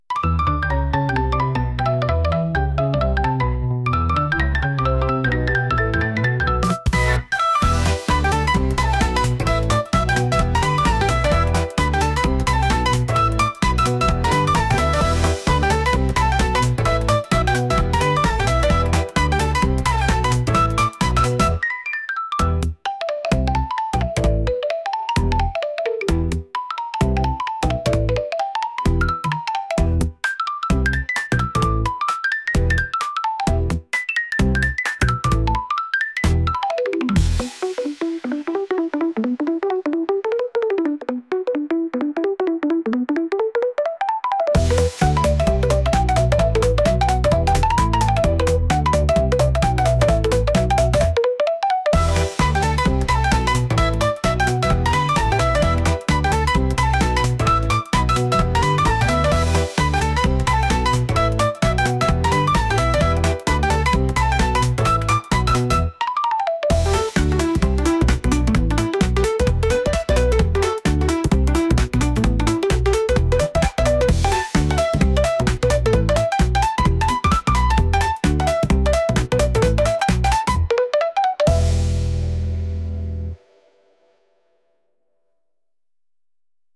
協力して行動するような元気な曲です。